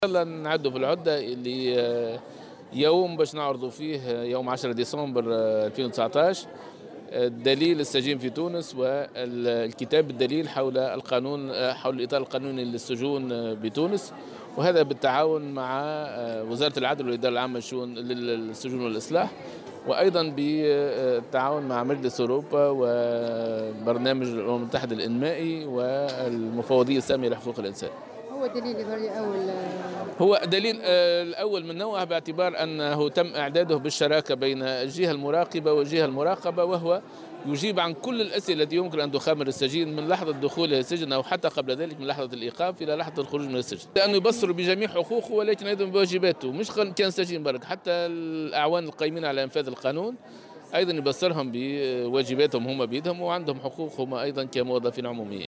وأضاف في تصريح اليوم لمراسلة "الجوهرة أف أم" على هامش المنتدى السنوي الثاني لرابطة الهيئات العمومية المستقلة، المنعقد بمدينة الحمامات، أن الهدف من هذا الدليل هو الإجابة عن مختلف أسئلة السجين منذ لحظة إيقافه إلى حين الإفراج عنه بخصوص حقوقه وواجباته وكذلك بالنسبة للأعوان القائمين على تنفيذ القانون.